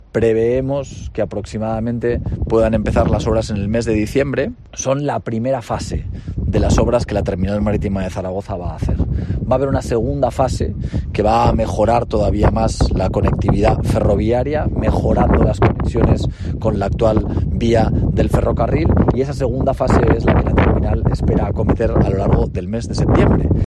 El alcalde de Zaragoza Jorge Azcón, valora la ampliación de la Terminal Marítima de Zaragoza a finales de 2022